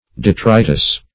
Detritus \De*tri"tus\, n. [F. d['e]tritus, fr. L. detritus, p.